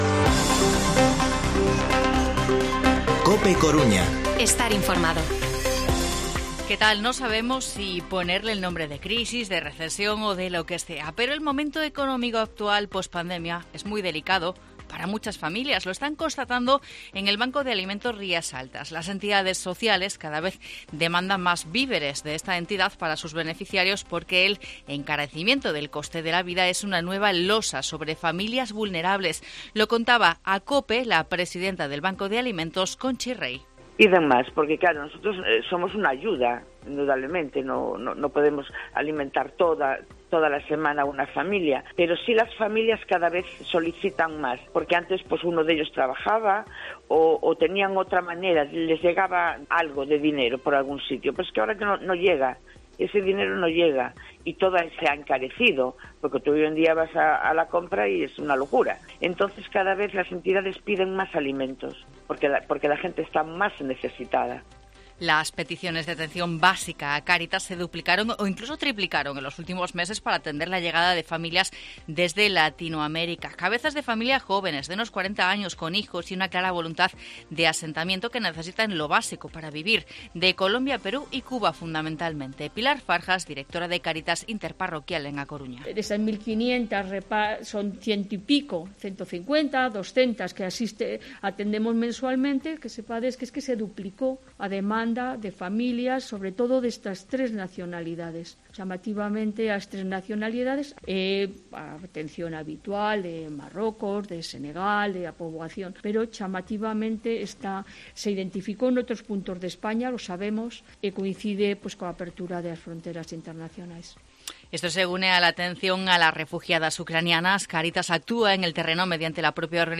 Informativo Mediodía COPE Coruña viernes, 4 de mayo de 2022 14:20-14:30